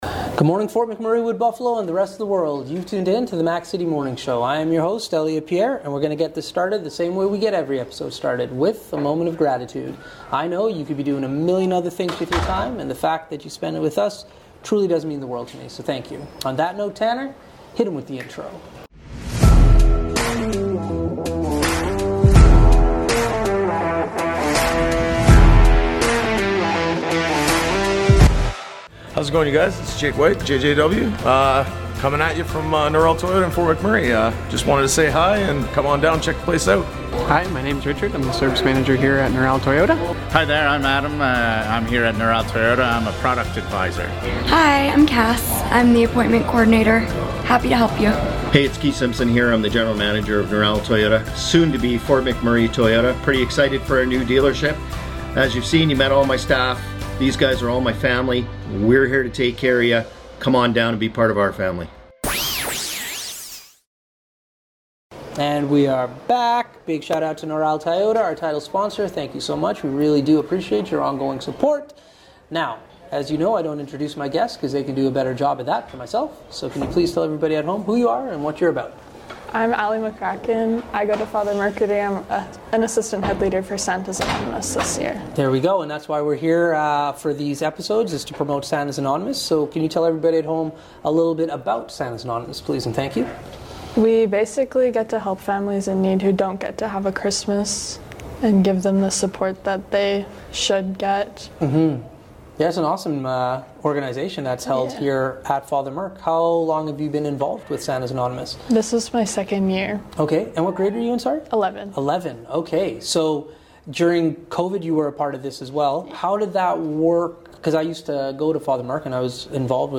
We are on location at Father Mercredi High School